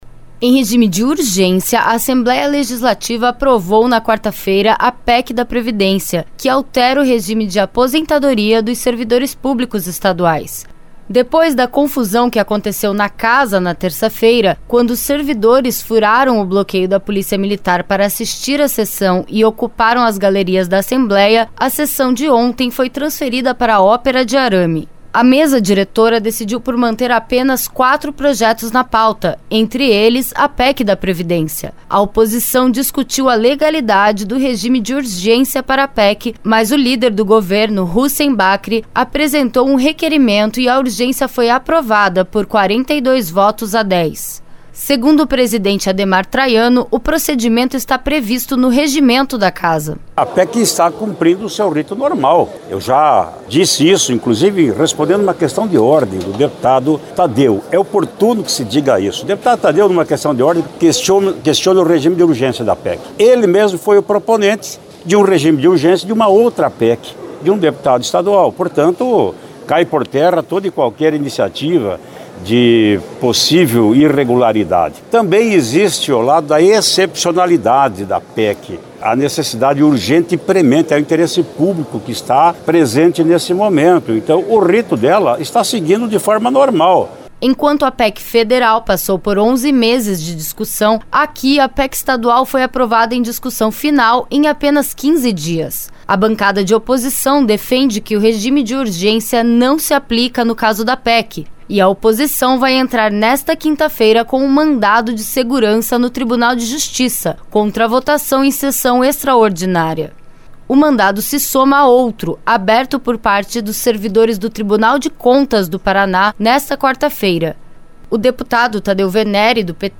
O deputado Tadeu Veneri (PT), líder da oposição na Assembleia, disse que a situação é resultado da falta de diálogo do governo com os servidores.
O Secretário chefe da Casa Civil do estado, Guto Silva, explicou porque o governo considera a reforma necessária.